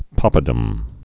(päpə-dəm)